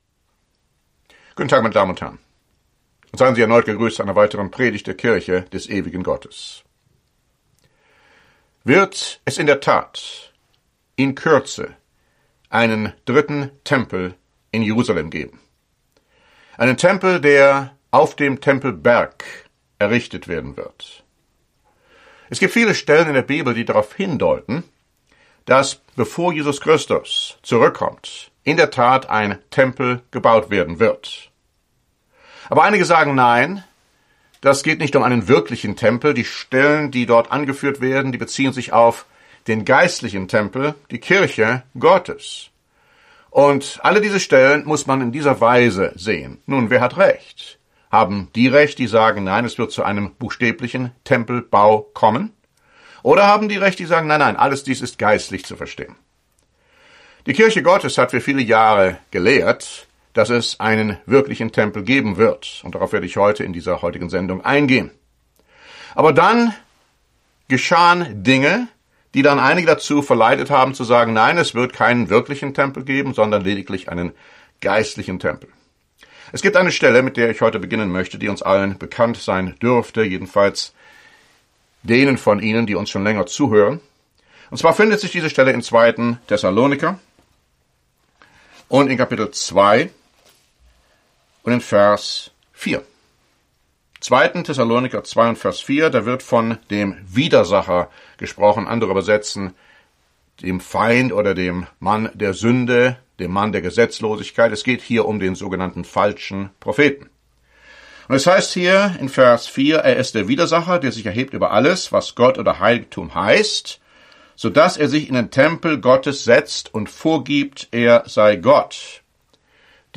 Diese Predigt zeigt die vielen biblischen Beweise auf, die zweifellos darlegen, dass in der Tat vor der Ankunft des Messias ein dritter Tempel in Jerusalem errichtet werden wird… und zwar bald! Wir befassen uns auch mit der Unterdrückung der Opfer durch eine fremde Militärmacht und dem Gräuel der Verwüstung zur Zeit der großen Trübsal.